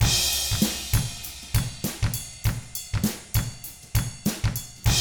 99FUNKY4T1-L.wav